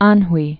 (änhwē) also An·hwei (-hwā, -wā)